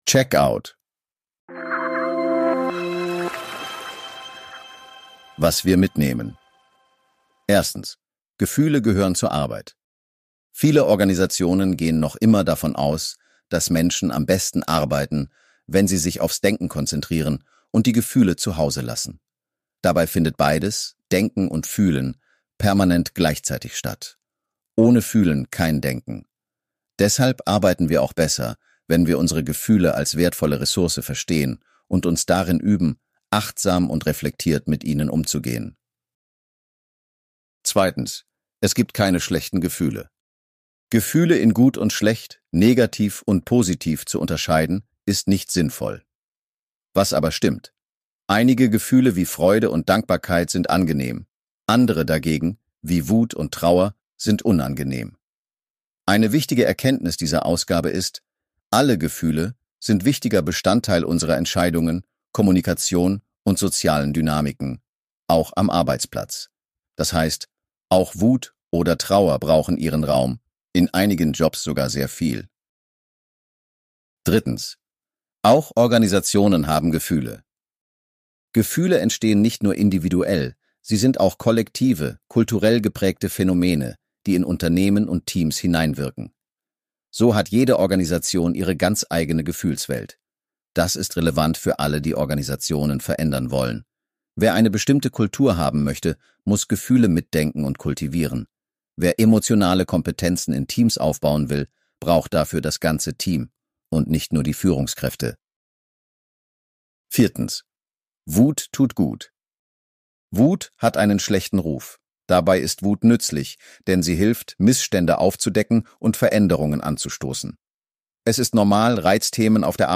Diesen Beitrag liest eine erfundene Stimme vor, die Redakteur*innen